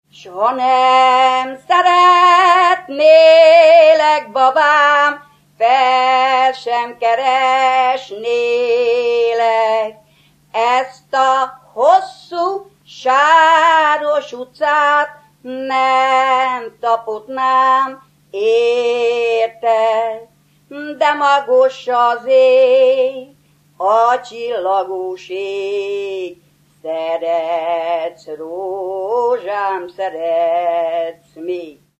Erdély - Szolnok-Doboka vm. - Magyardécse
Műfaj: Lassú csárdás
Stílus: 4. Sirató stílusú dallamok